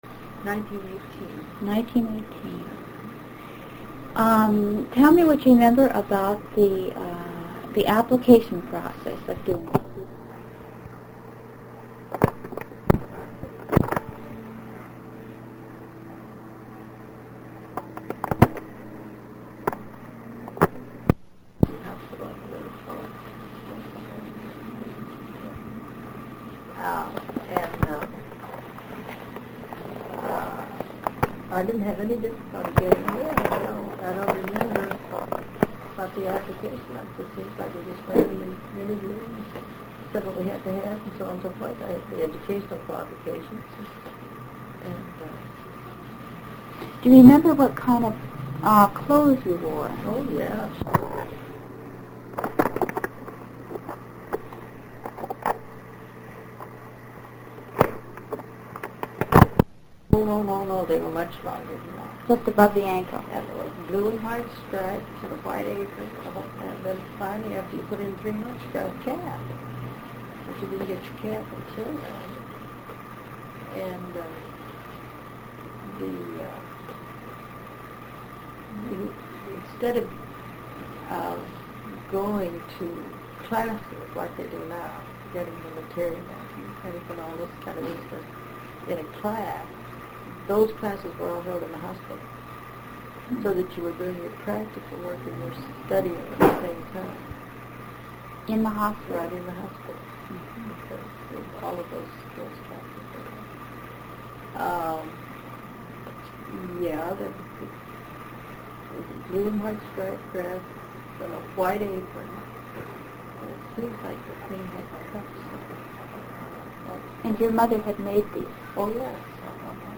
This is particularly problematic on the second side of the recording (2b), where there is background noise.